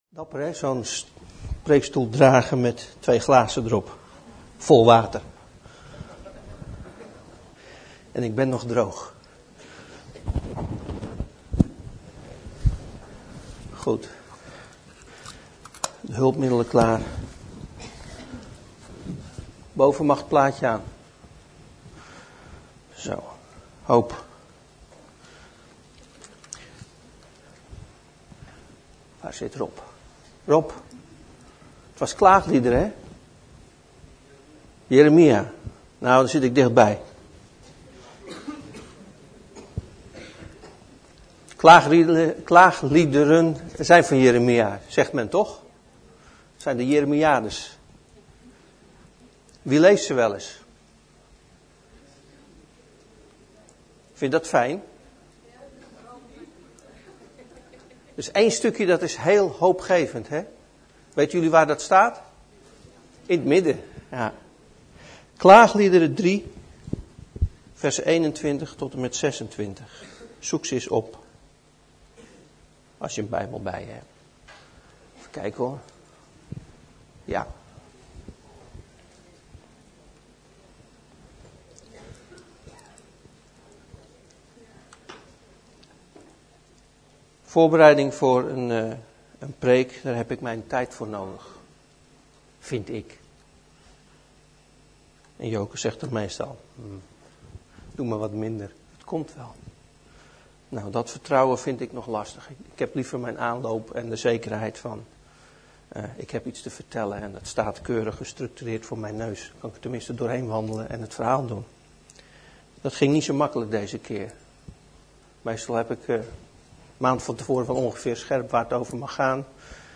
Overzicht van preken van Preken op Christengemeente Bethel